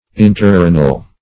Search Result for " interrenal" : The Collaborative International Dictionary of English v.0.48: Interrenal \In`ter*re"nal\, a. (Anat.)